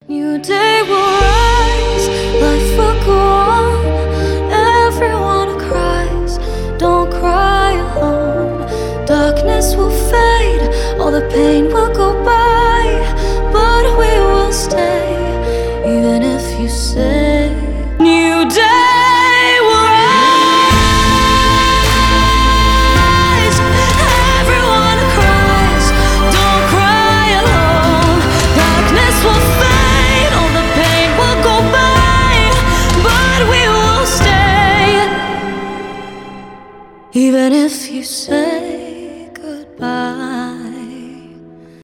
вдохновляющие , поп